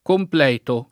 [ kompl $ to ]